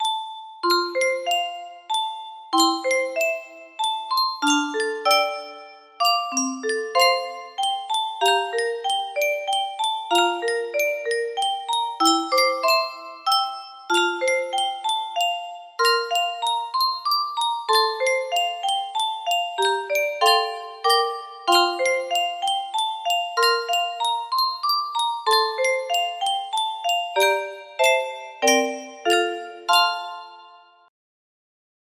브람스 자장가 music box melody
Grand Illusions 30 (F scale)